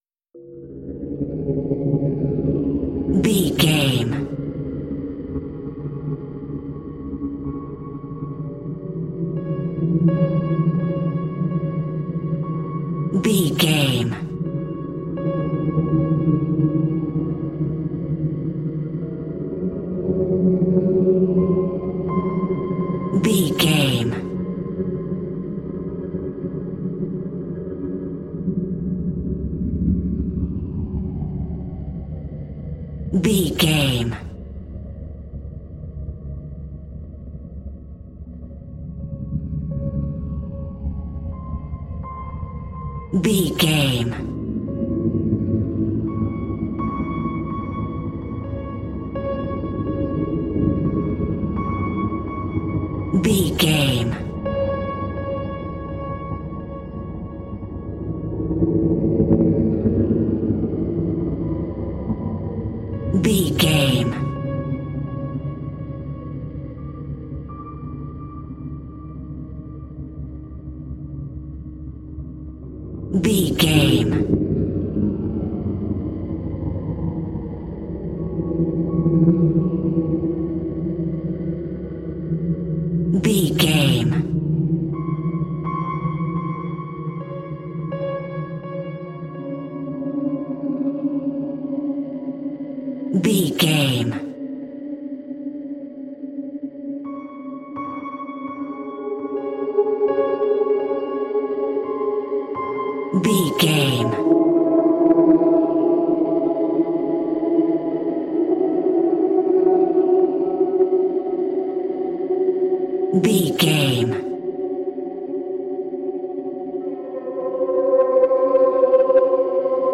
Atonal
tension
ominous
dark
haunting
eerie
synthesizer
horror music
Horror Pads
Horror Synths